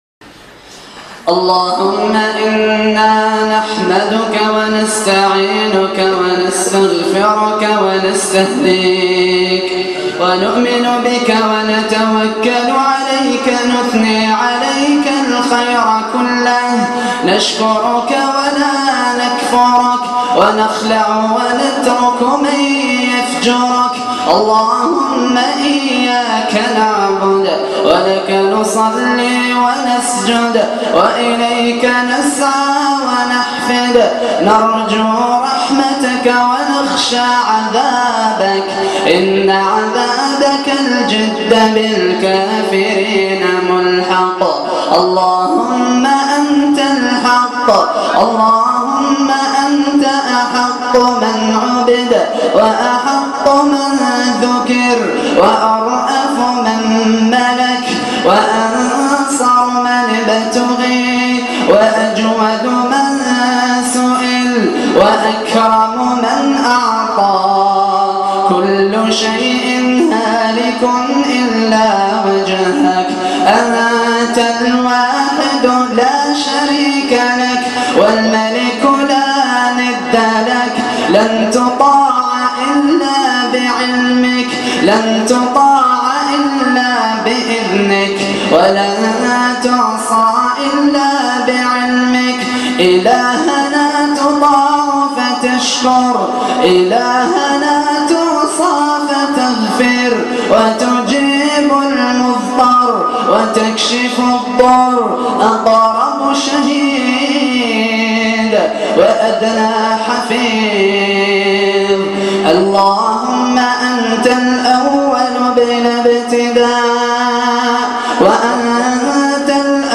دعاء ليلة 27 رمضان 1437هـ
تسجيل لدعاء خاشع ومؤثر